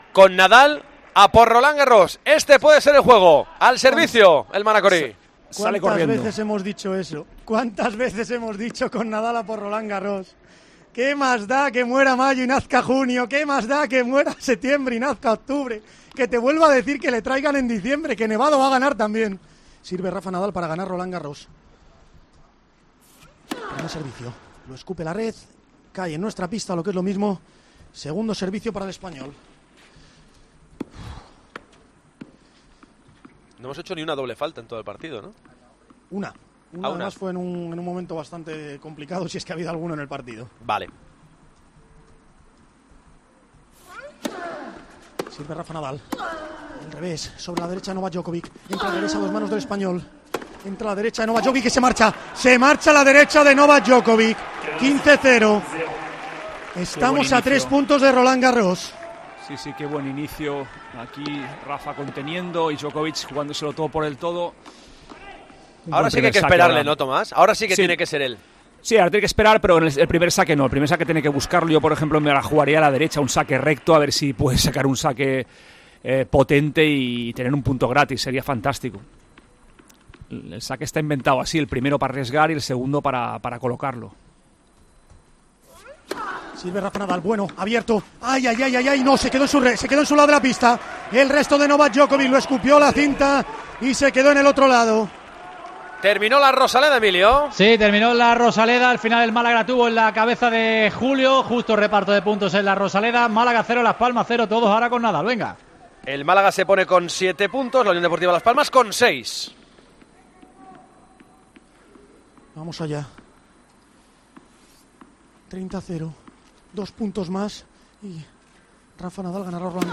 Con la narración